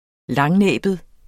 Udtale [ -ˌnεˀbəð ]